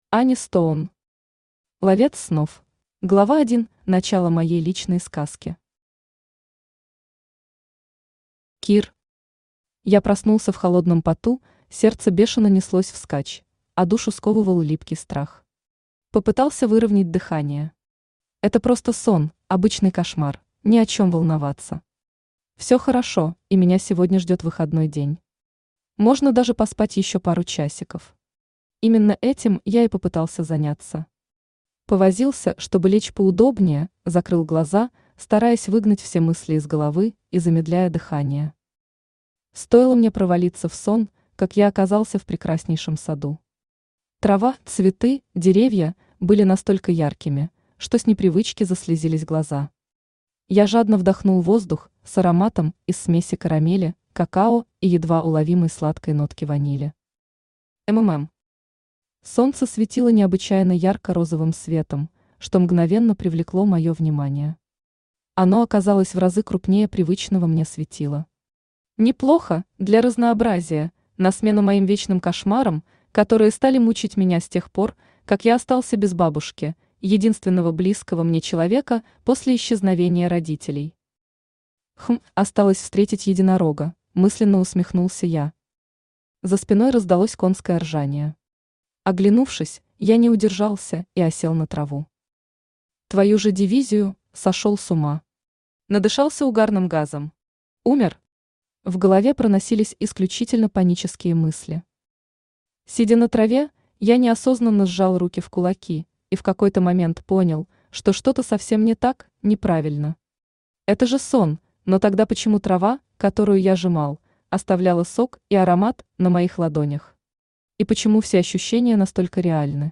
Аудиокнига Ловец снов | Библиотека аудиокниг
Aудиокнига Ловец снов Автор Ани Стоун Читает аудиокнигу Авточтец ЛитРес.